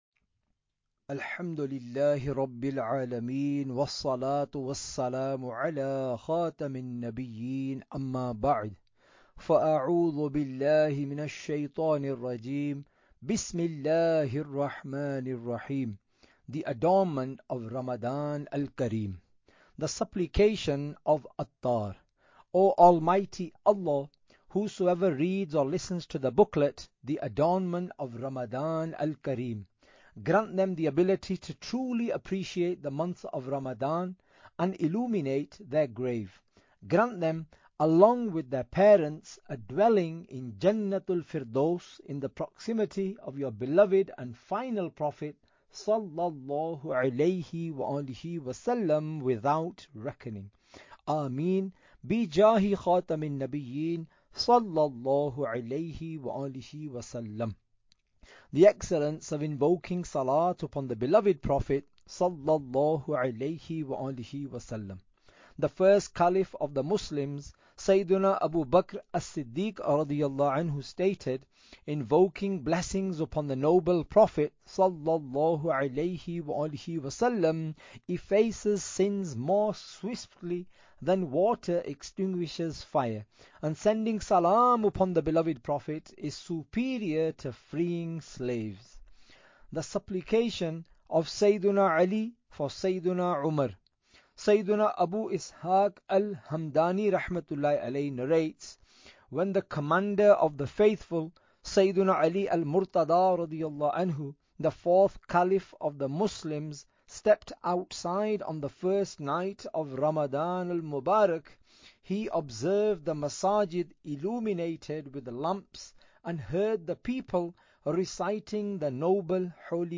Audiobook - The Adornment of Ramadan Al Karim (English)